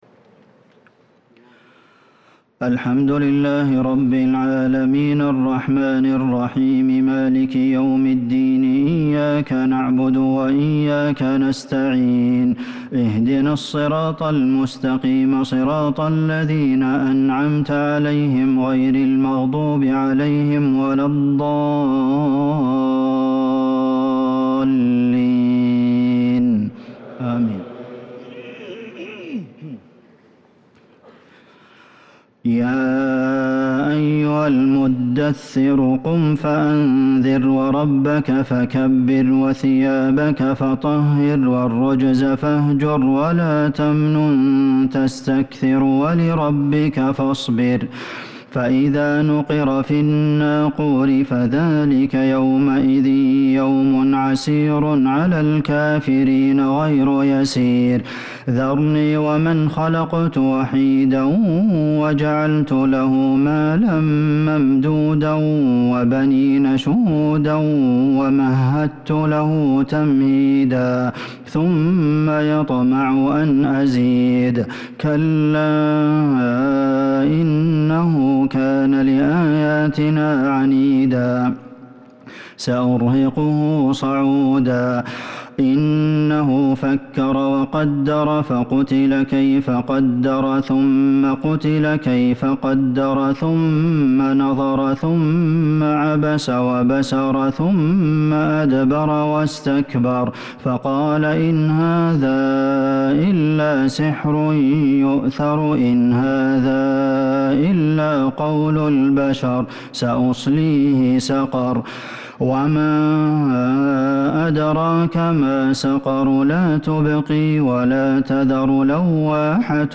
صلاة التراويح ليلة 28 رمضان 1443 للقارئ خالد المهنا - الثلاث التسليمات الاولى صلاة التهجد